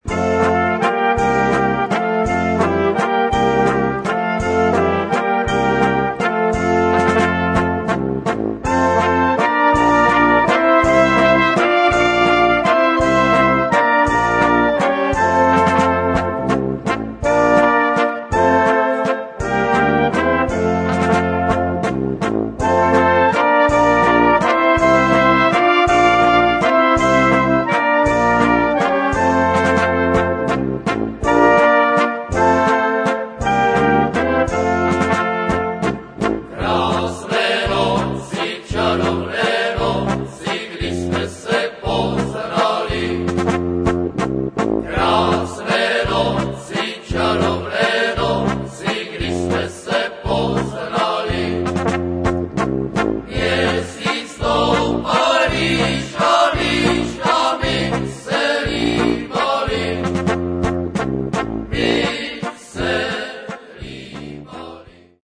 valčík 6:14-upr.